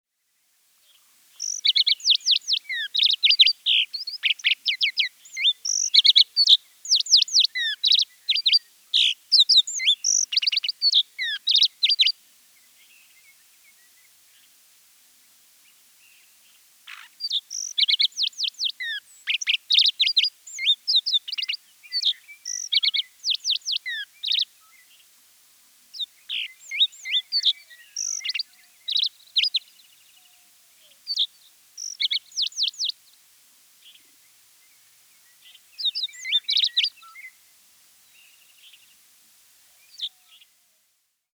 На этой странице вы найдете подборку звуков жаворонка – от звонких утренних трелей до нежных переливов.
Звуки природы: песня жаворонка в лесах Аризоны